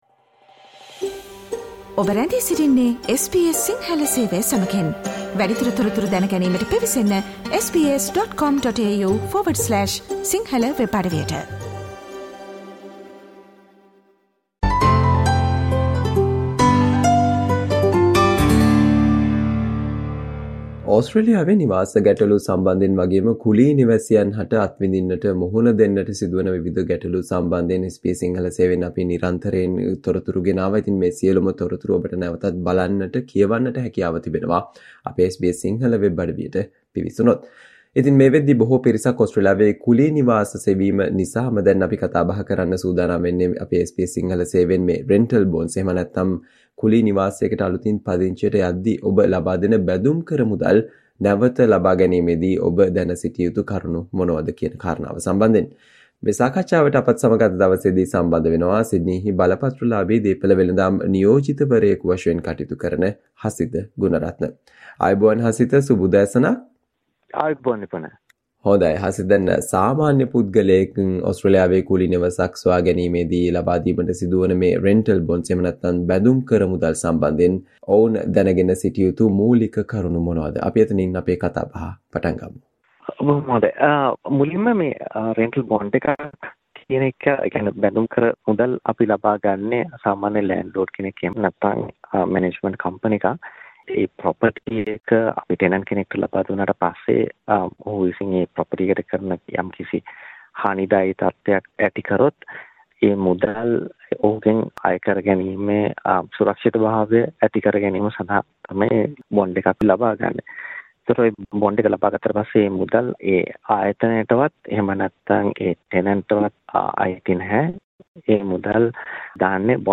SBS Sinhala discussion on the things you should know about the "Rental bond" in Australia